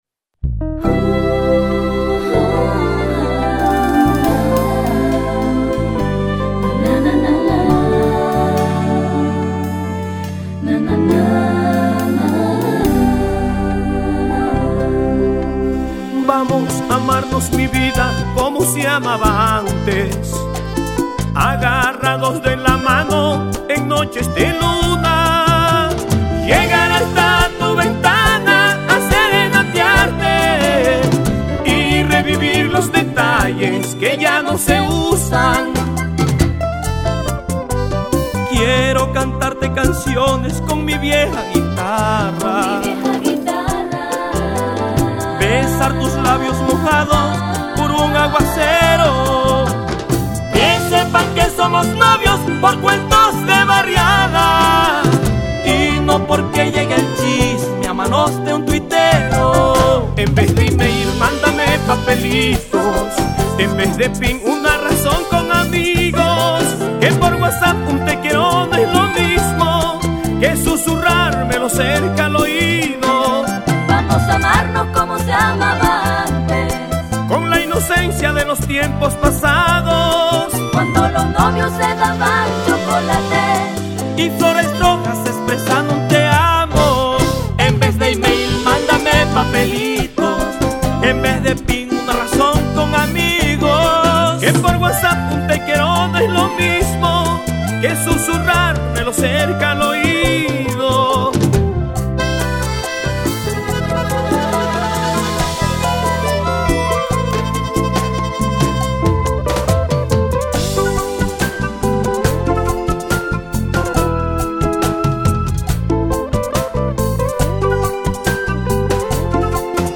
con tres acordeoneros, seis coristas y tres solistas